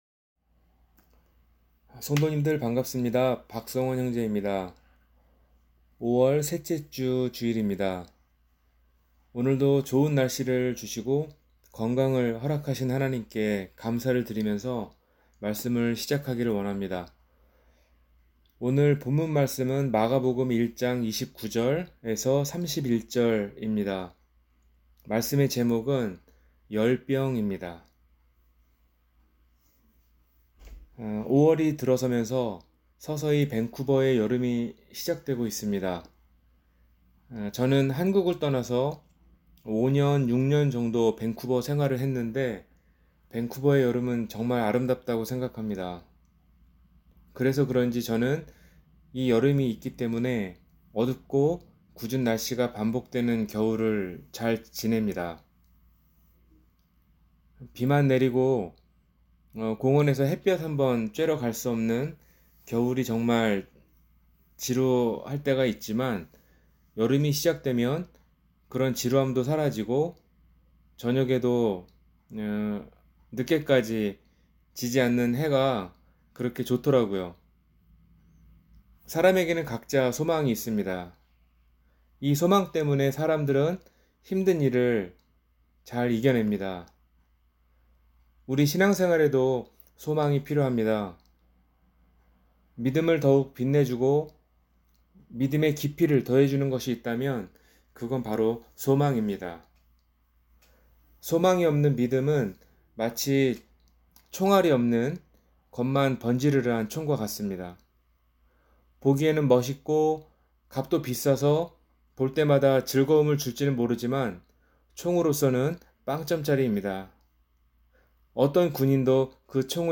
열병 – 주일설교